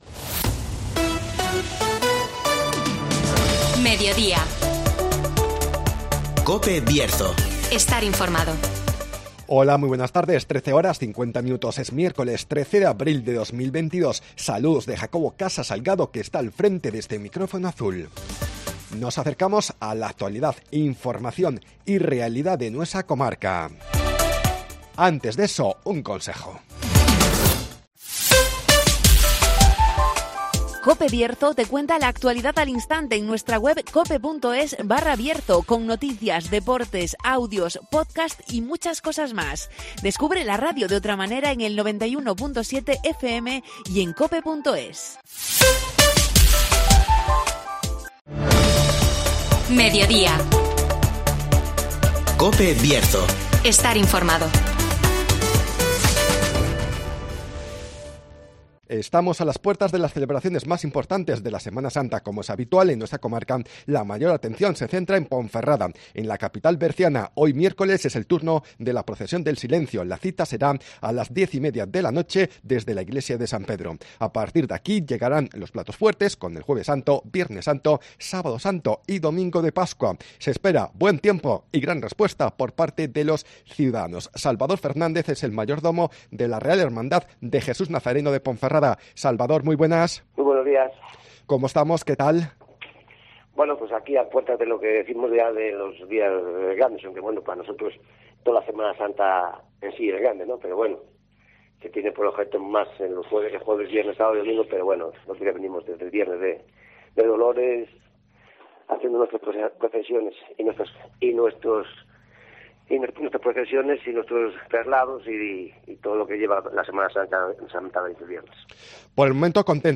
Llegan los platos fuertes de la Semana Santa ponferradina (Entrevista